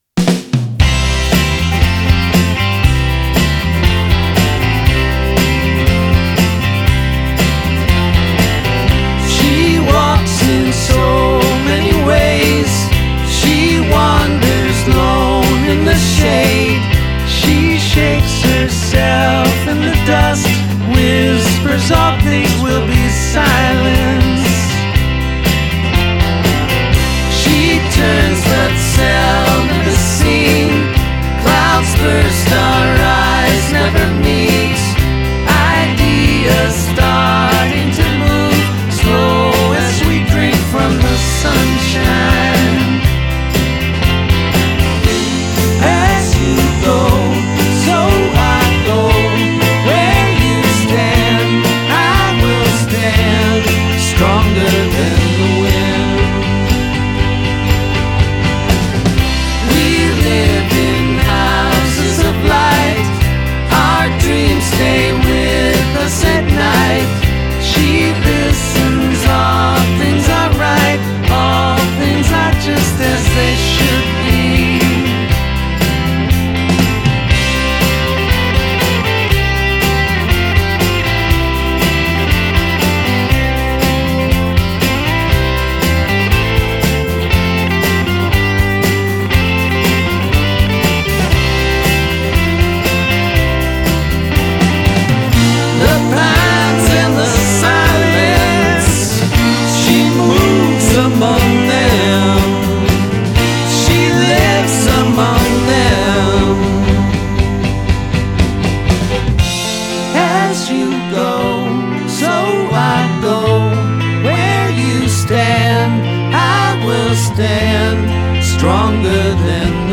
alt country